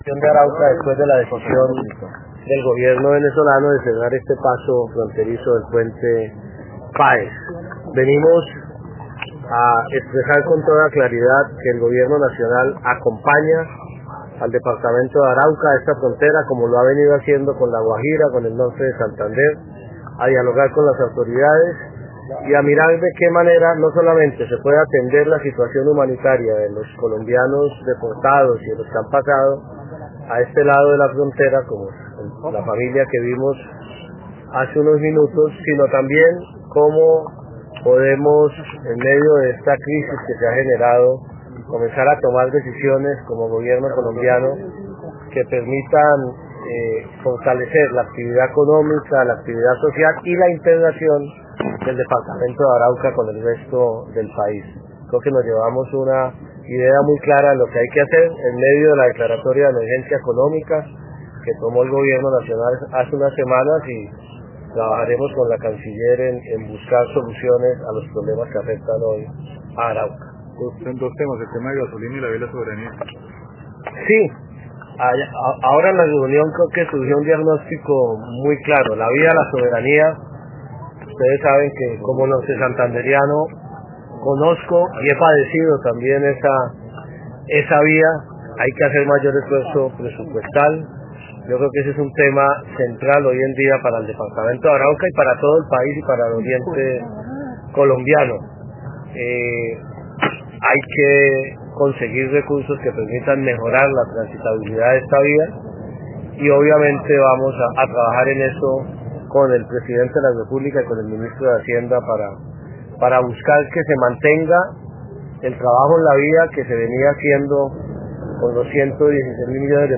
Canciller María Ángela Holguín explicó las medidas del Gobierno Nacional para buscar soluciones a la situación fronteriza